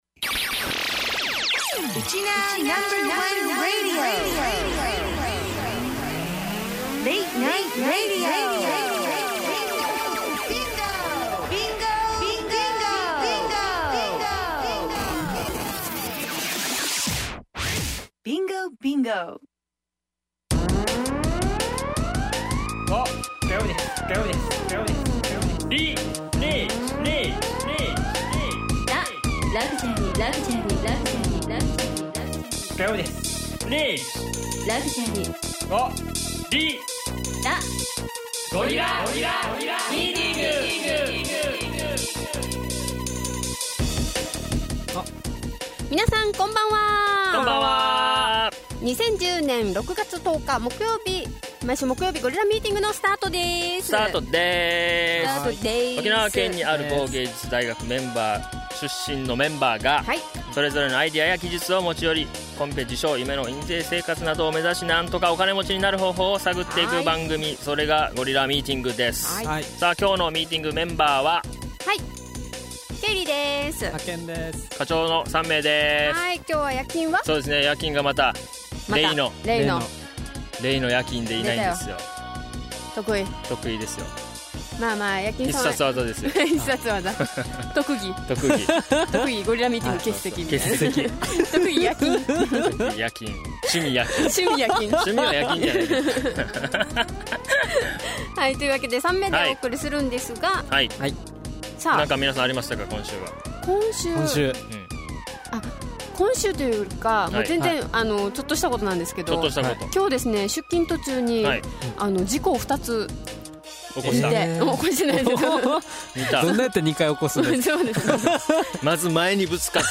ゴ リラＭＴＧ 0610 こちらでも再生できます 暫く待っても再生出来ない時はもう一度ＰＬＡＹボタンを押して下さい ゴリラＭＴＧをiTunesに登録する 過去放送の番組はこちら ★ ユーストリーム動画はこちら★ こちらの再生ボタンを押してください ミィーティング・メンバー／番組の紹介 沖縄の某芸術大学出身の4人が それぞれのアイディアや 技術を持ち寄り毎週木曜日の 夜23時に”お金持ち”を目指して MTG（ミーティング）を開きます。